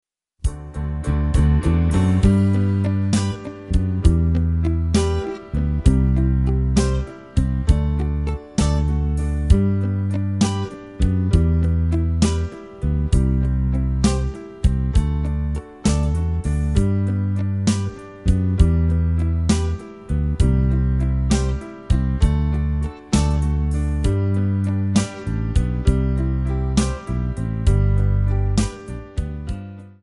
Backing track files: All (9793)